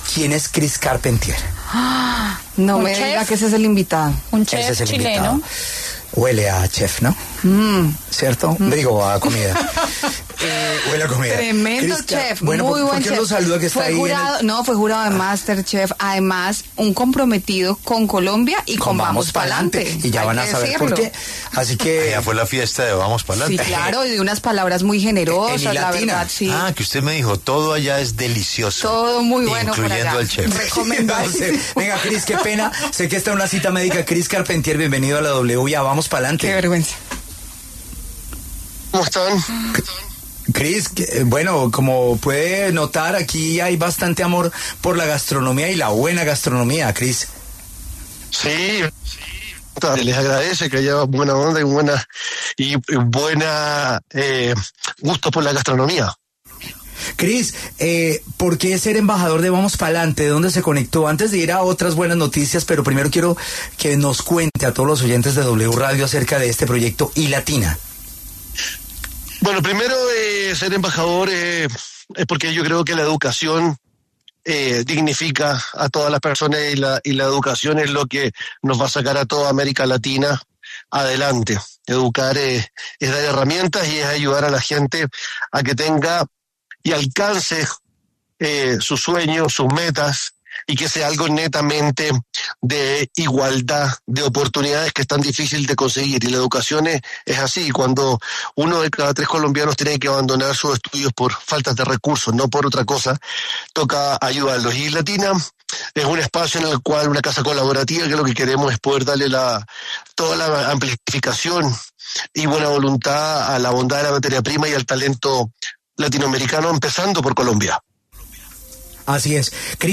Reviva la entrevista completa con Christopher Carpentier aquí: